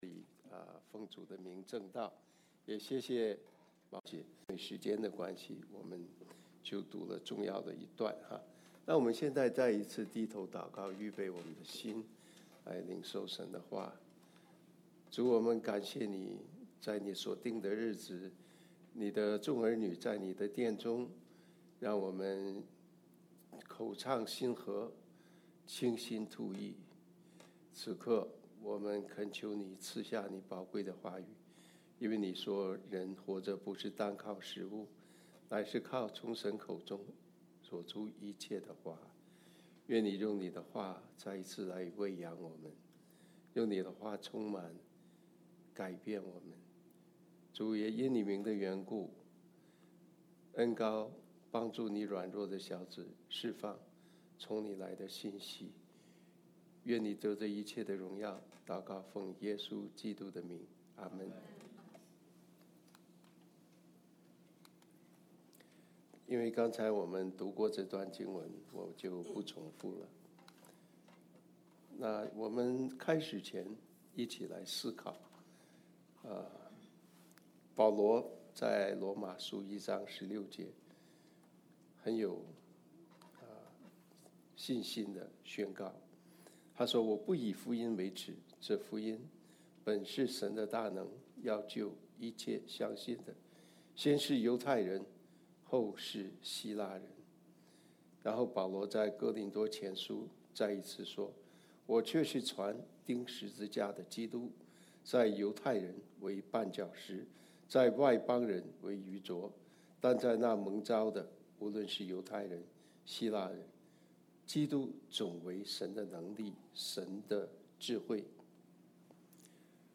August 10, 2025 耶穌佈道的三堂課 Passage: John 4:6-26; 1 Peter 3:15 Service Type: 主日证道 Download Files Notes « 浪子回頭 我心所愿 » Submit a Comment Cancel reply Your email address will not be published.